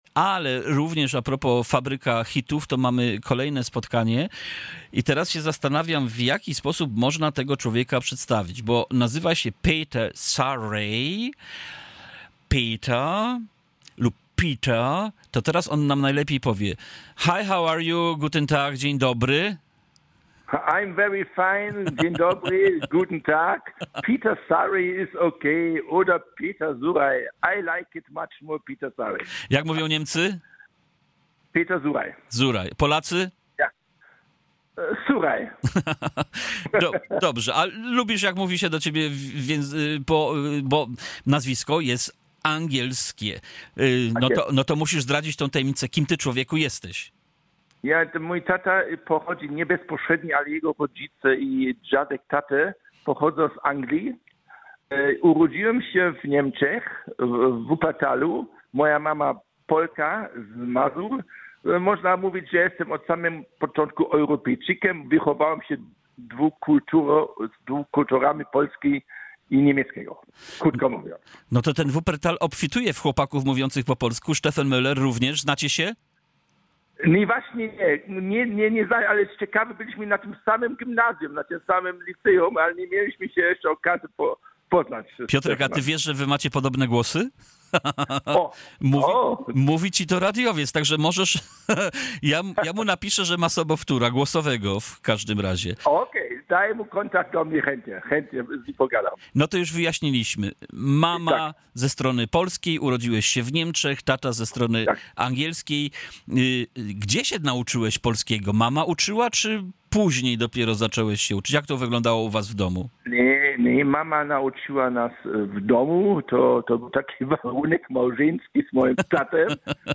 We’re excited to share that AGE CONNECT & ROCKAWAY were featured in a special interview on Radio Darmstadt! During the broadcast, the band shared insights about their musical journey, the creative process behind their latest single “We Believe in Music”, and the emotions tied to releasing a song that speaks to unity, joy, and the power of sound.